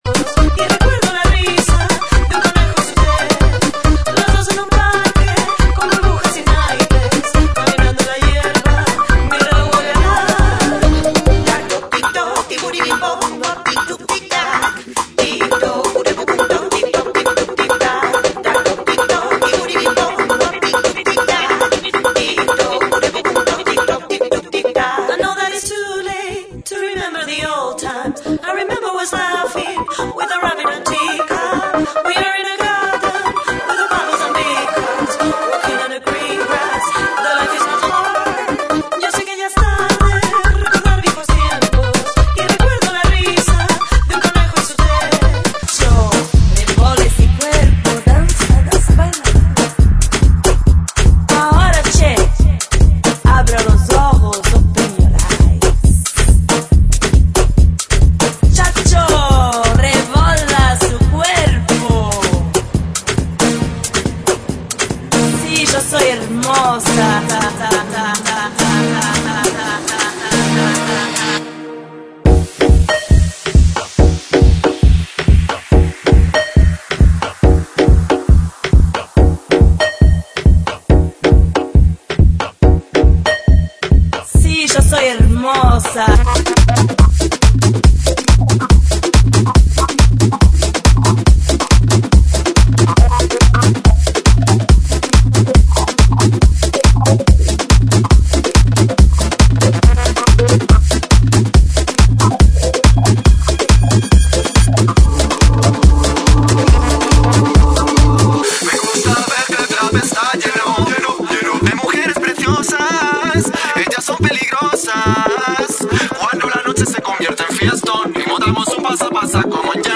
GENERO: ELECTRO POP